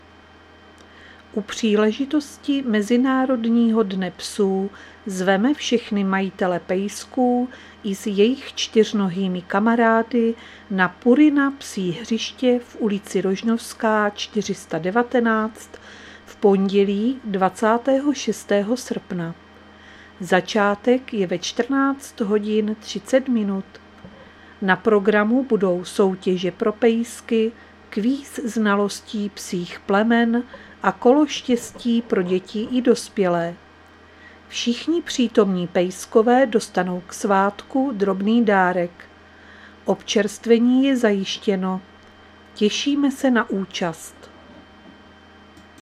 Záznam hlášení místního rozhlasu 23.8.2024
Zařazení: Rozhlas